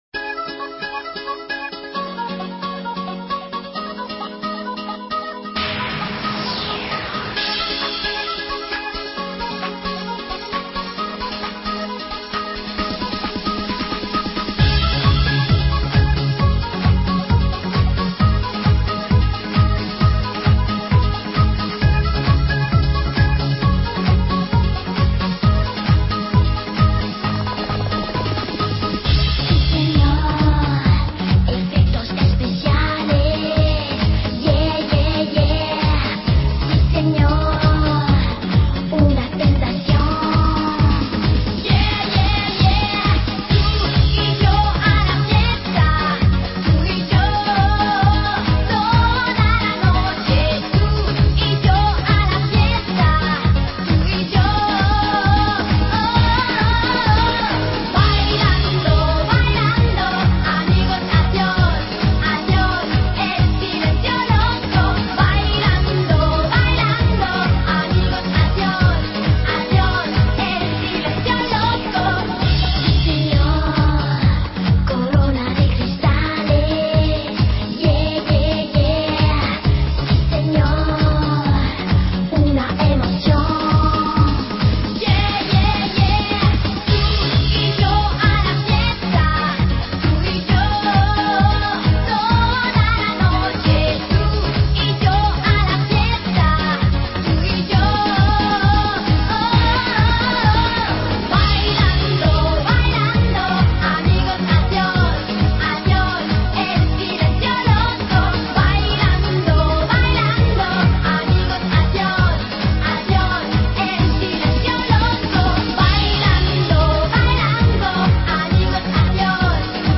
Список файлов рубрики Exclusive EuroDance 90-х